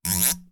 Cartoon Sound 02
funny happy spring sound effect free sound royalty free Funny